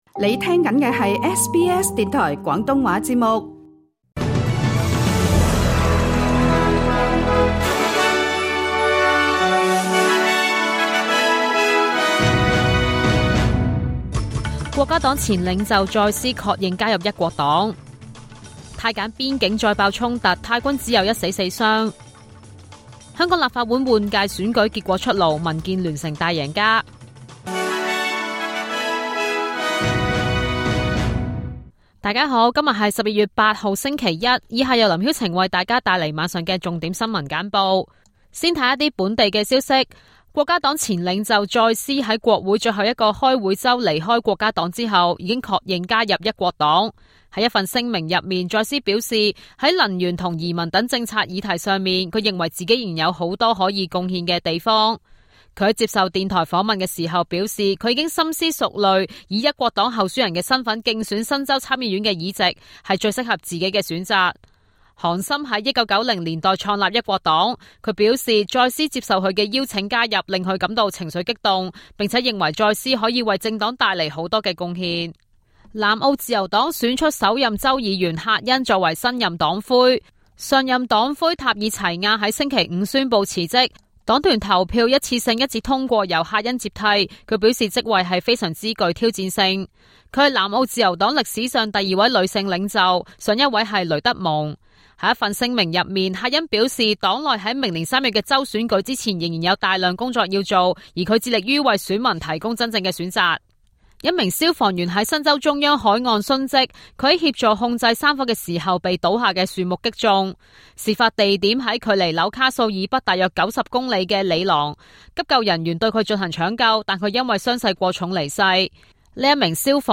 SBS晚間新聞（2025年12月8日）